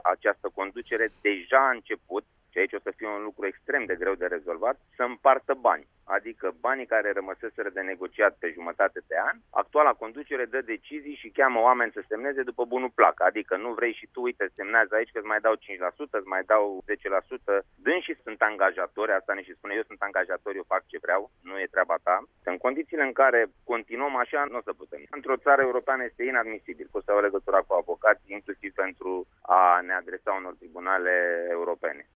Într-un interviu acordat Radio România Actualităţi, el a precizat că aşteaptă ca noul ministru al transporturilor să medieze acest conflict.